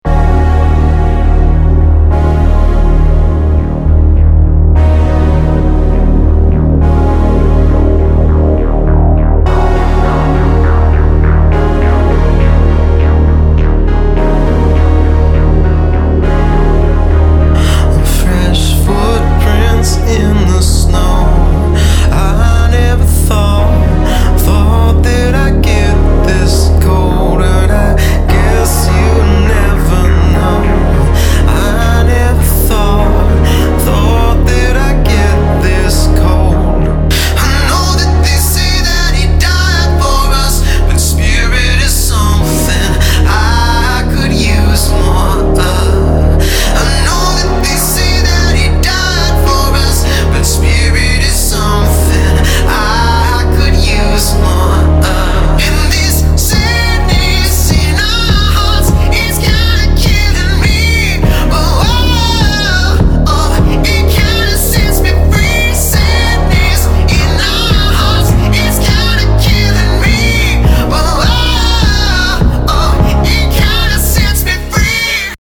UPDATED WITH HIGHER QUALITY !!